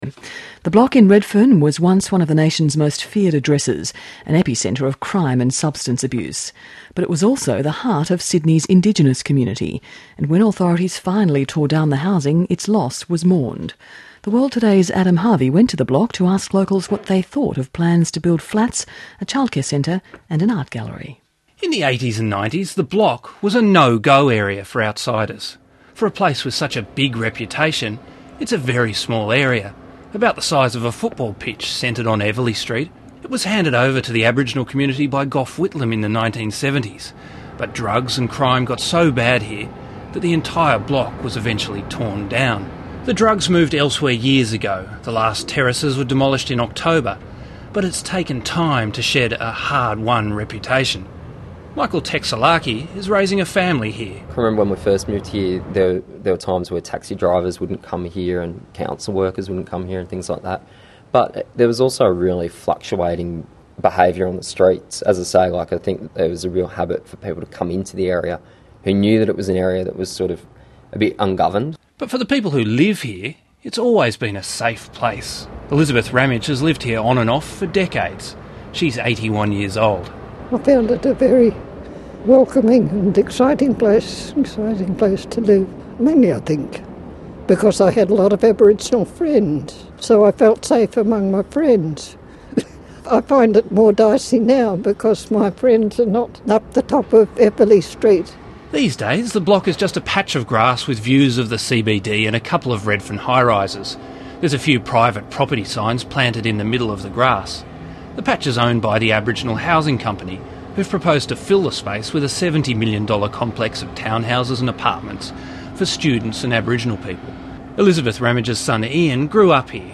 Audio: Archive ABC Report on 'The Block' redevelopment
ABC Radio National's 'The World Today' produced this shallow report in January 2012